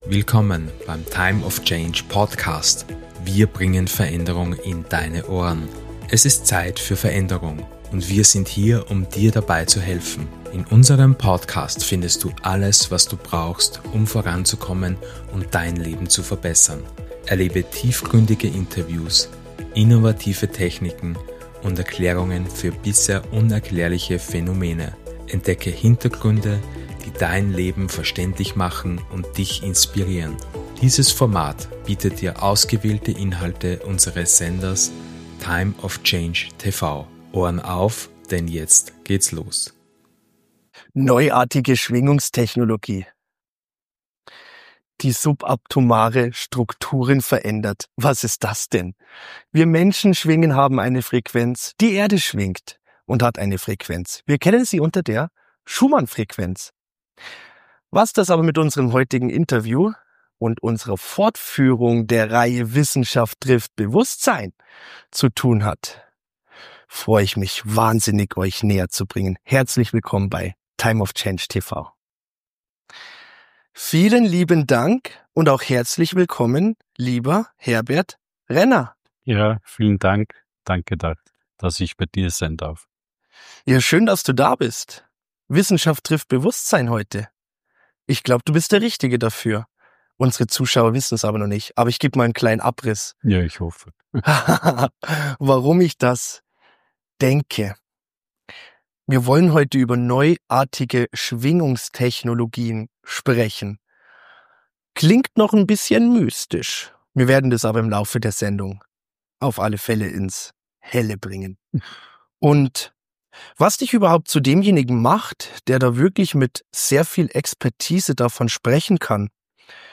Eine Antwort darauf gibts in diesem Interview.